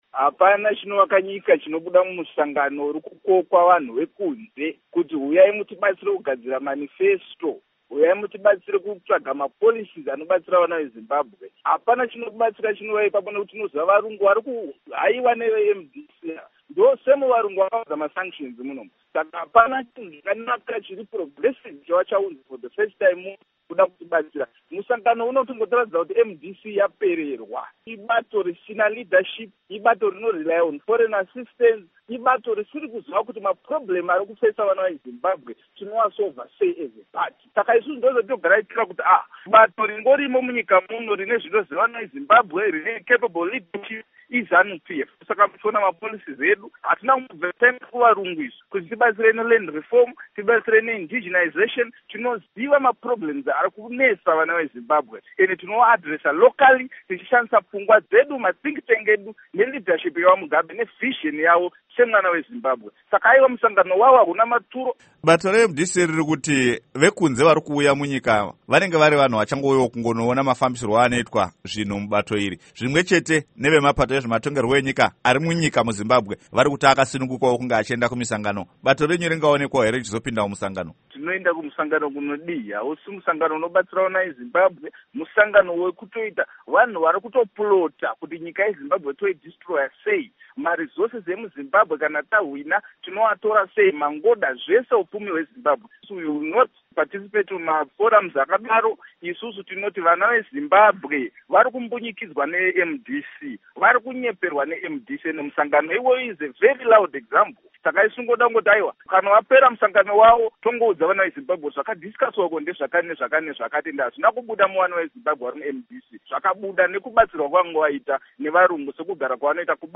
Hurukuro naVaPsychology Maziwisa